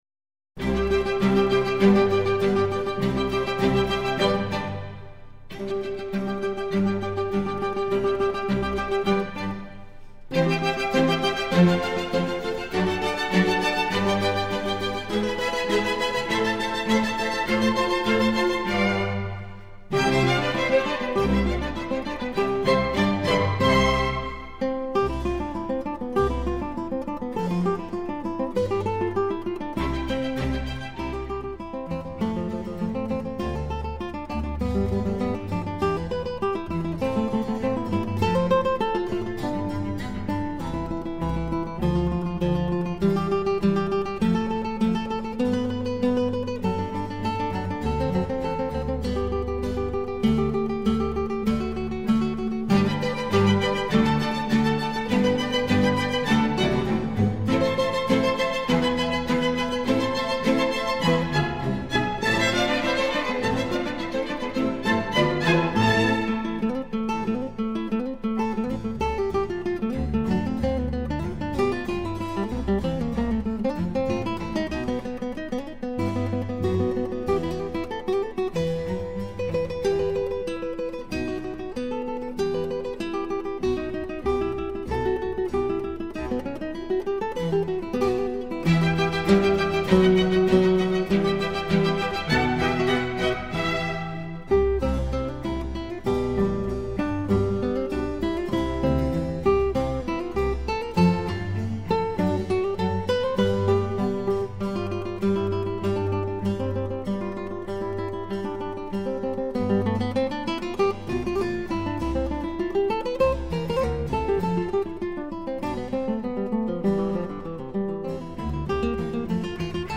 gitara
klawesyn